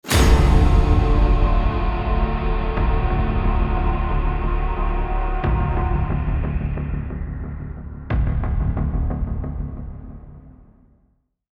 Download Horror sound effect for free.
Horror